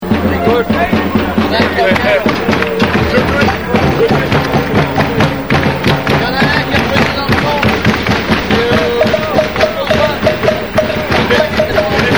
JEU DE MAINS,  JEU DE SUPPORTERS